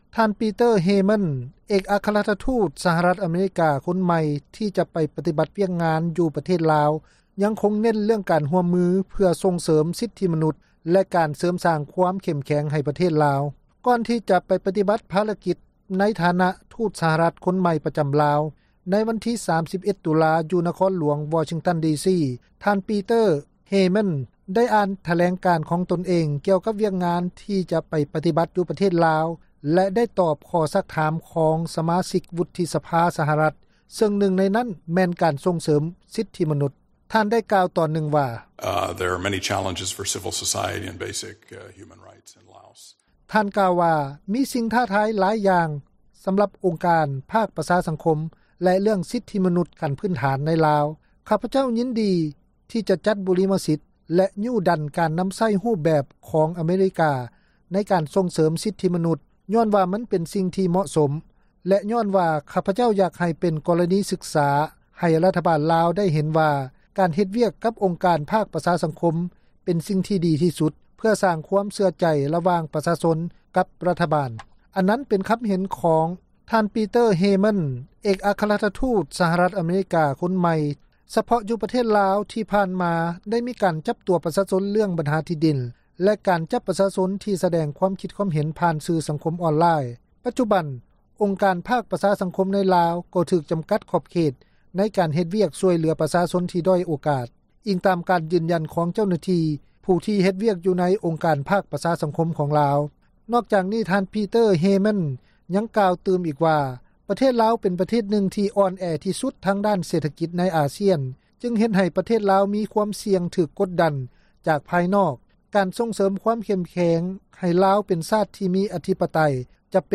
ກ່ອນທີ່ຈະໄປປະຕິບັດພາລະກິດ ໃນຖານະທູດສະຫະຣັຖຄົນໃໝ່ ປະຈຳລາວ, ໃນວັນທີ 31 ຕຸລາ ຢູ່ ນະຄອນຫລວງ ວໍຊິງຕັນ ດີ ຊີ, ທ່ານ Peter Haymond ໄດ້ອ່ານຖແລງການຂອງຕົນ ກ່ຽວກັບວຽກງານ ທີ່ຈະໄປປະຕິບັດ ຢູ່ ປະເທດລາວ ແລະ ໄດ້ຕອບຂໍ້ຊັກຖາມ ຂອງ ສະມາຊິກວຸດທິສະພາ ສະຫະຣັຖ ຊຶ່ງນຶ່ງໃນນັ້ນແມ່ນການສົ່ງເສີມ ສິດທິມະນຸດ. ທ່ານກ່າວຕອນນຶ່ງ ວ່າ: